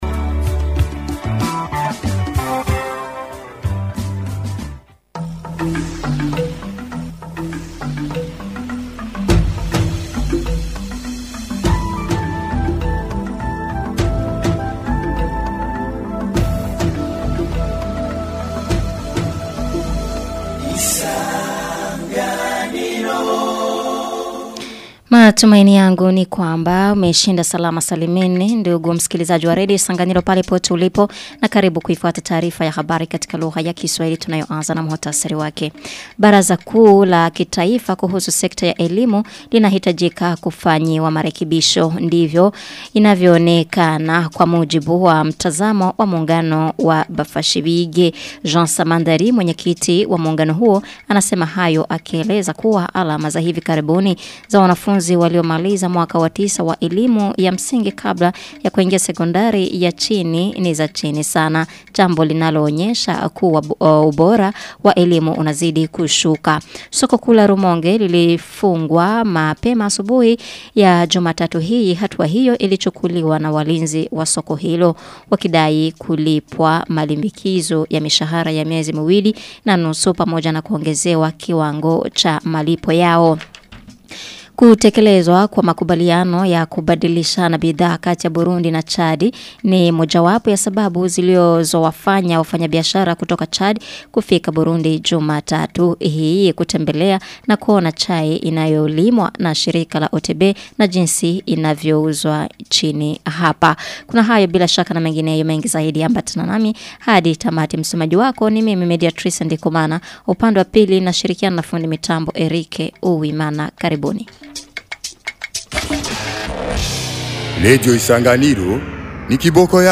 Taarifa ya habari ya tarehe 1 Septemba 2025